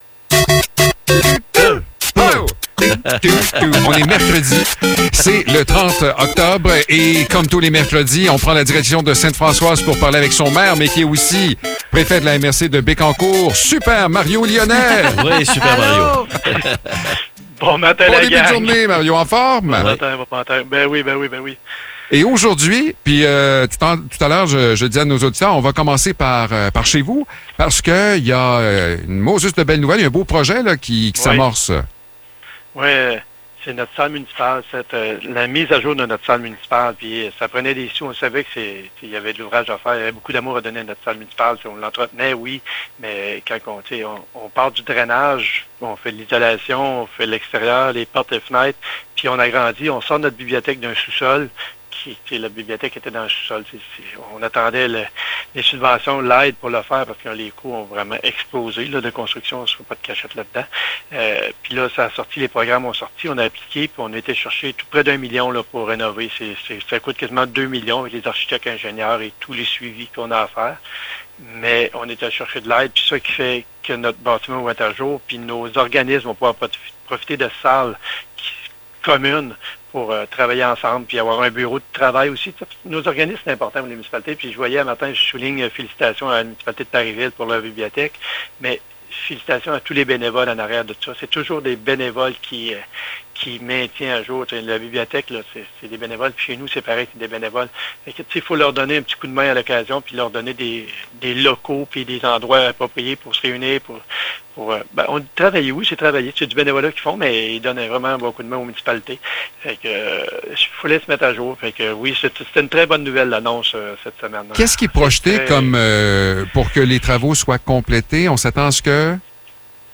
Mario Lyonnais, maire de Ste-Françoise et préfet de la MRC de Bécancour, nous parle d’une très belle nouvelle pour un organisme.